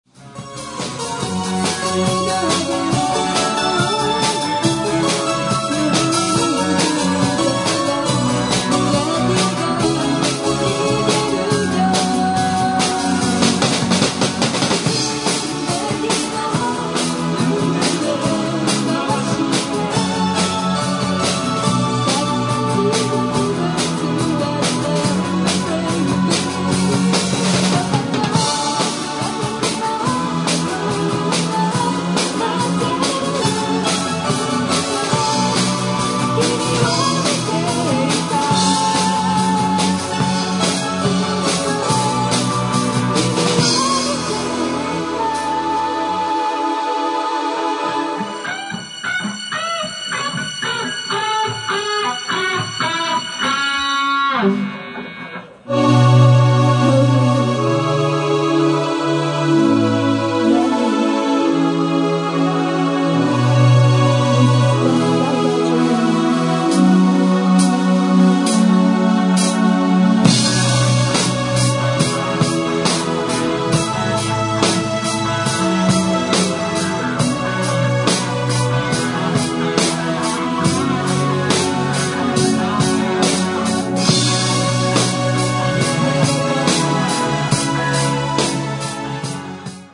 三声ハーモニー
本番は、PAが弱いハコなので、演奏もコーラスも音量バランスはイマイチでしたが、内容的にはなかなかの出来となりました。
keyboard,chorus
drums
guitar
bass